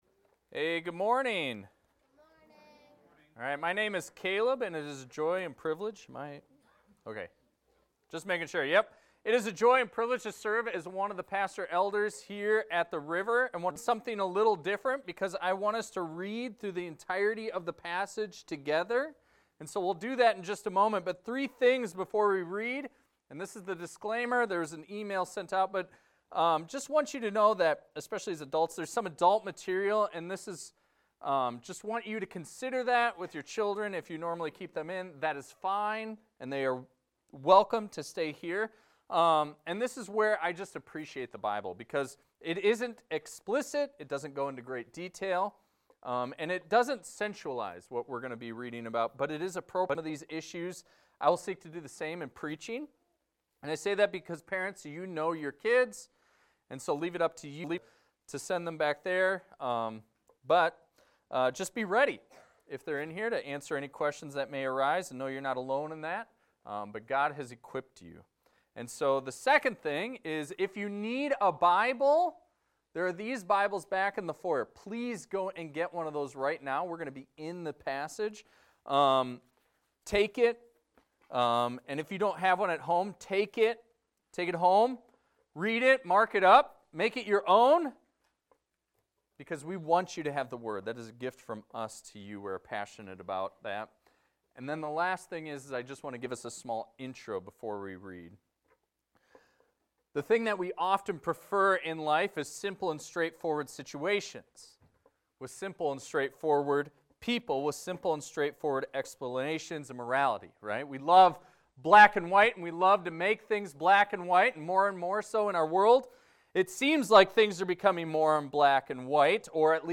This is a recording of a sermon titled, "No One is Good."